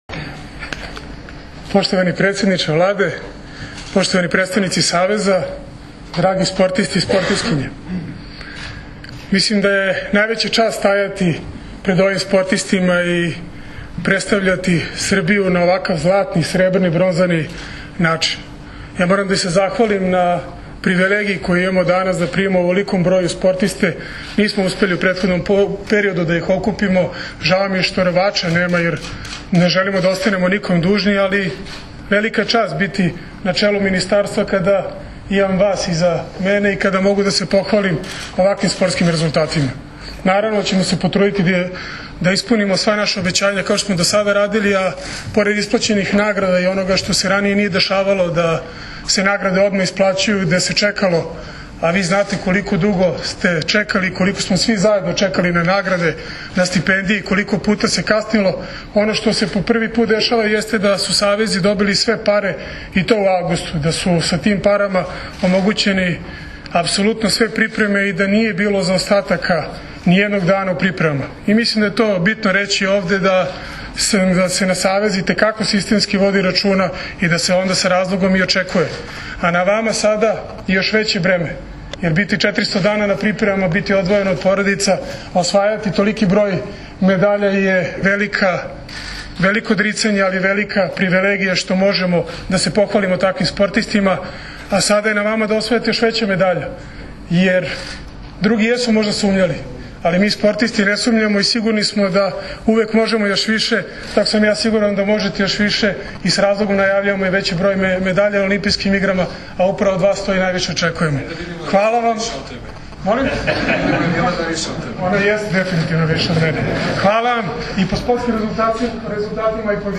Predsednik Vlade Srbije priredio prijem za bronzane odbojkašice
IZJAVA VANJE UDOVIČIĆA